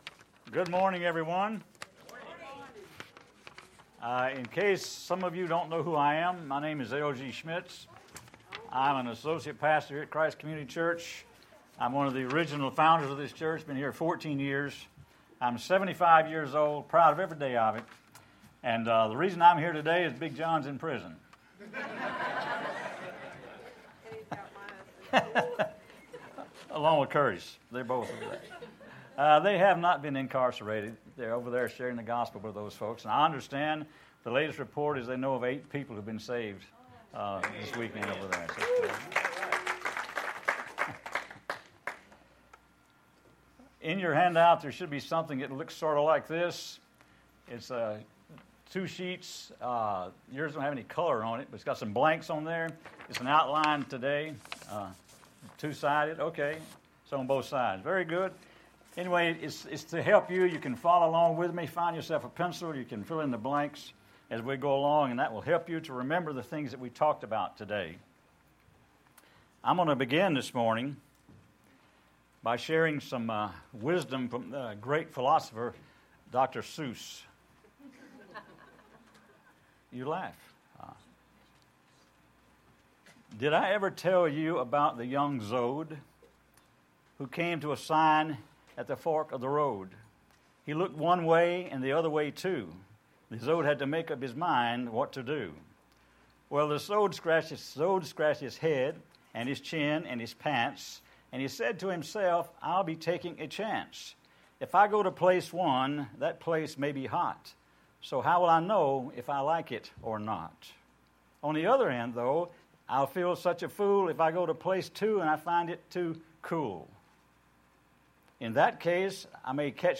9_30_12_Sermon.mp3